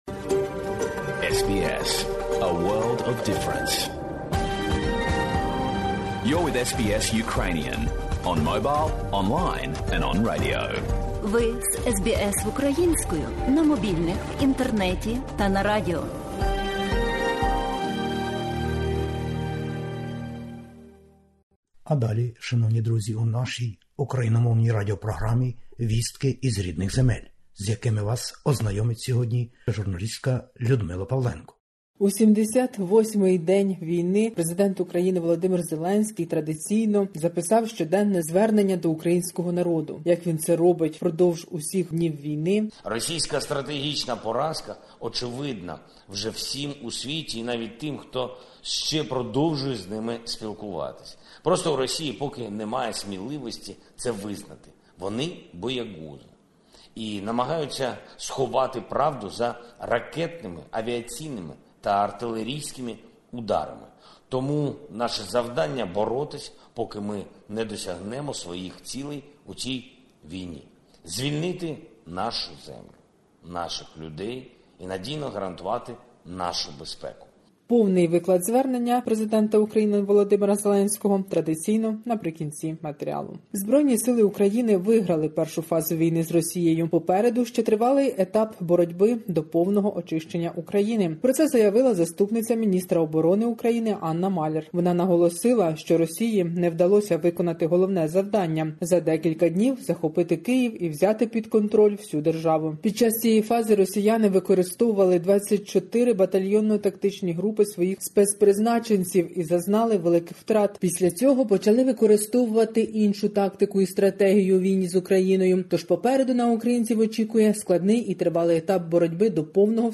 Добірка новин із героїчної України.